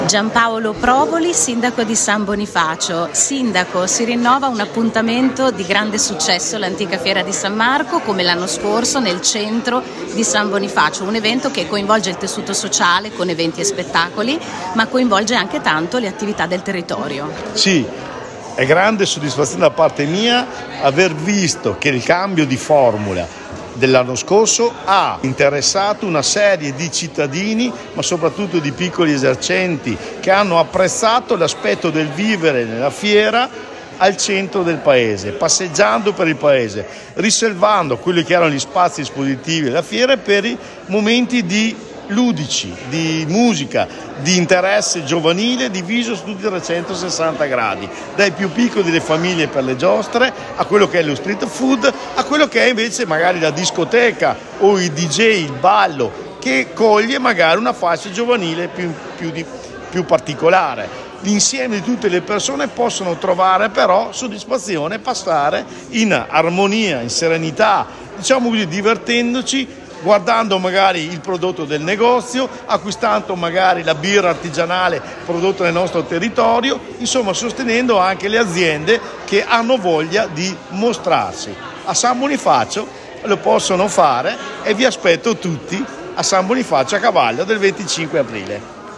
Al nostro microfono:
Gianpaolo Provoli, sindaco di San Bonifacio